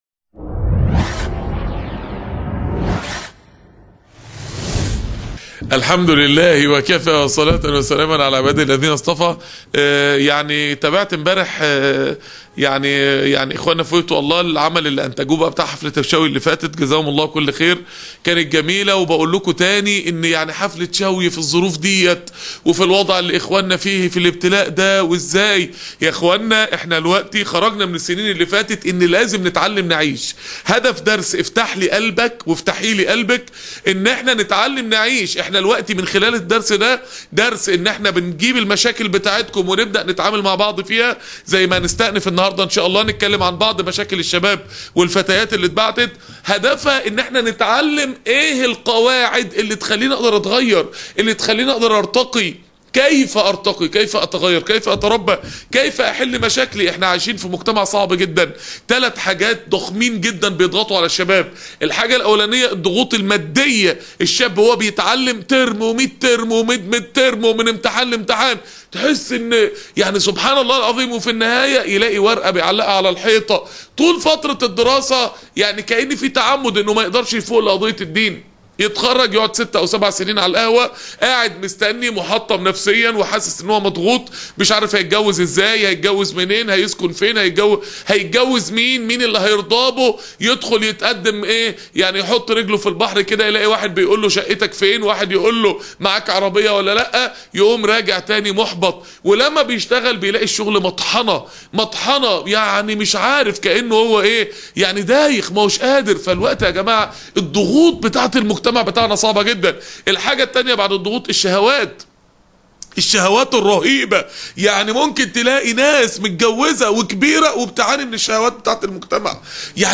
درس الغرفة الصوتية